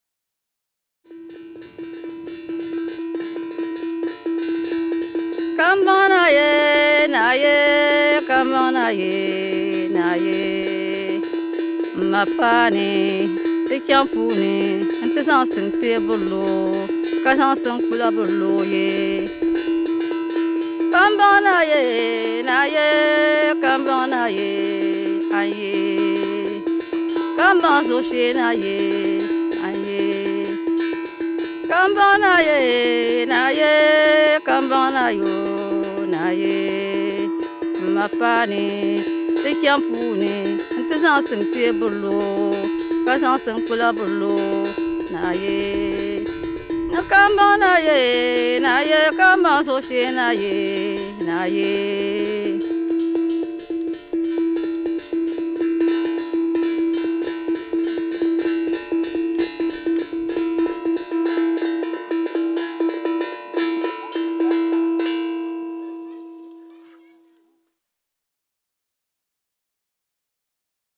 Banished 'Witches' Sing Of Their Pain — And Their Dreams
In a new recording, women accused of witchcraft in Ghana — and forced to leave their homes — created songs that tell who they are, how they have suffered and what their hopes are.